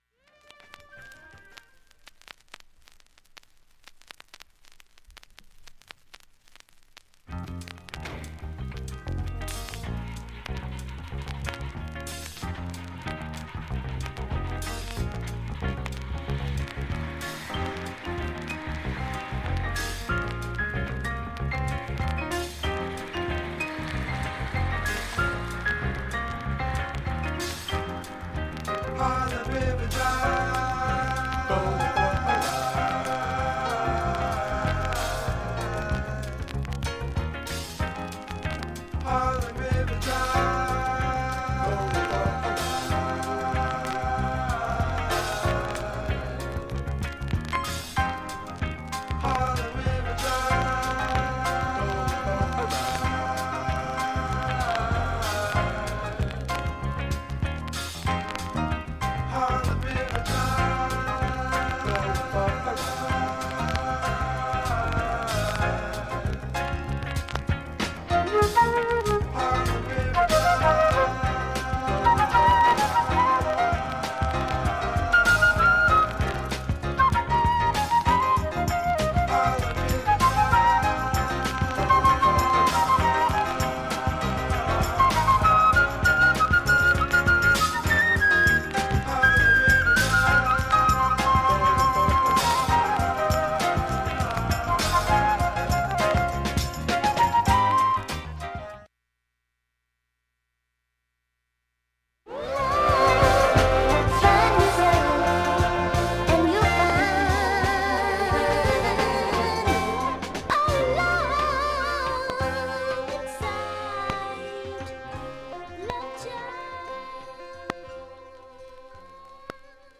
曲間無音部、静かな部でチリつきあります
普通に聴けます音質良好全曲試聴済み。
レアグルーヴ必聴盤